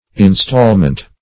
installment \in*stall"ment\, instalment \in*stal"ment\, n.